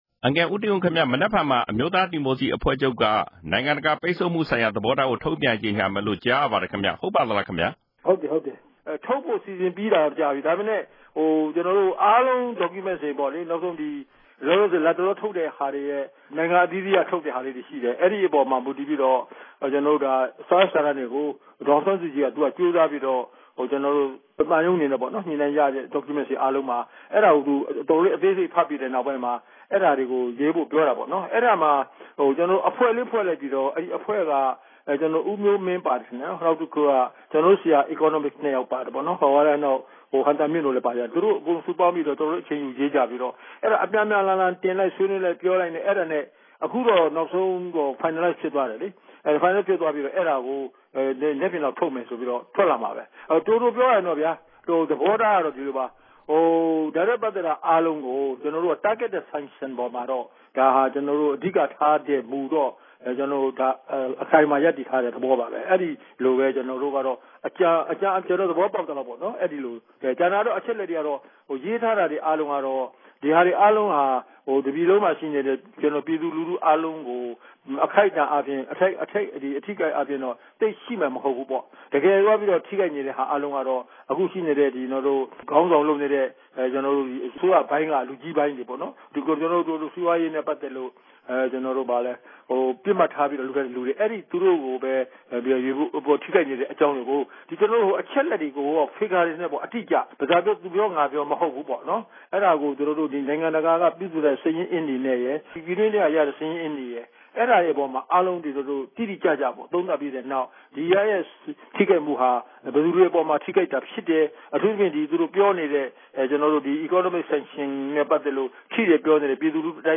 အဲဒီကြေညာချက်နဲ့ပတ်သက်ပြီး NLD ဒု-ဥက္ကဋ္ဌ ဦးတင်ဦးနဲ့ RFA ဆက်သွယ်မေးမြန်းချက်ကို နားဆင်နိုင်ပါတယ်။
ဆက်သွယ်မေးမြန်းချက်။